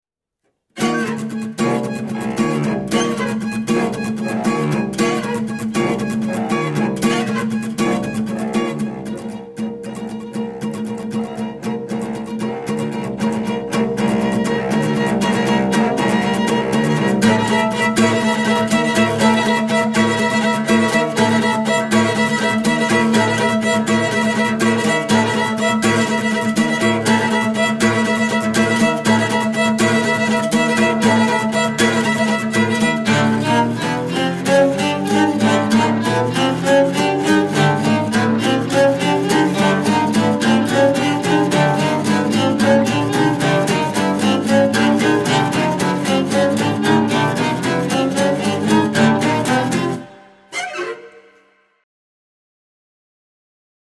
rzępolenie albo ja sie nie znam na muzyce zupełnie.... >>